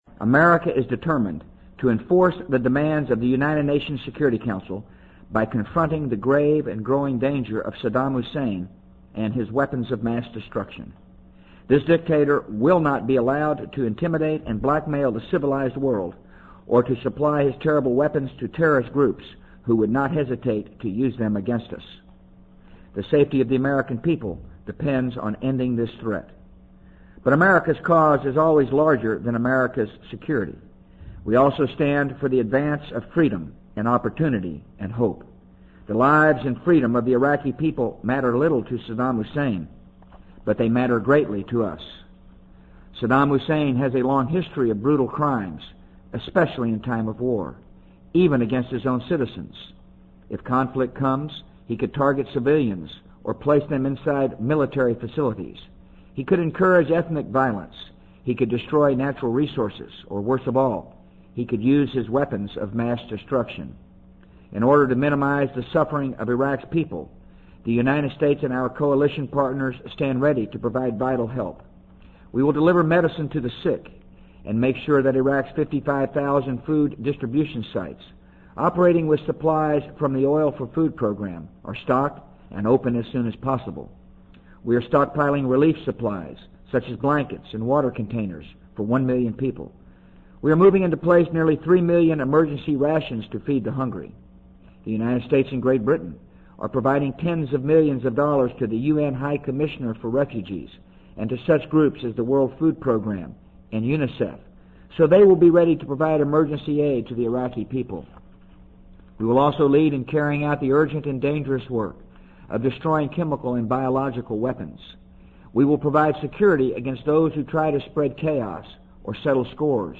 【美国总统George W. Bush电台演讲】2003-03-01 听力文件下载—在线英语听力室